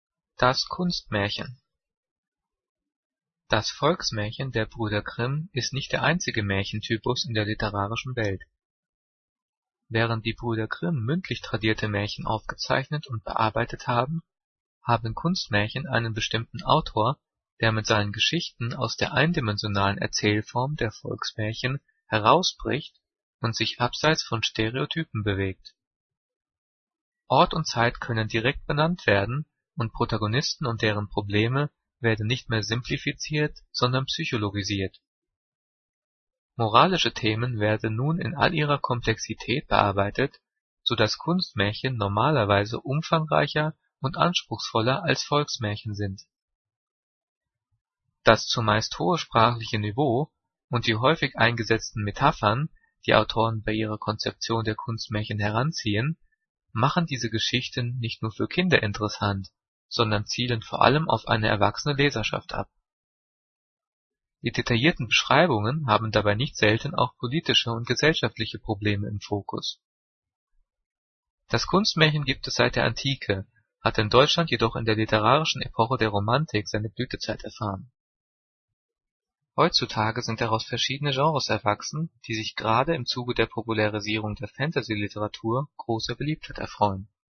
Diktat: "Das Kunstmärchen" - 7./8. Klasse - Fremdwörter
Gelesen: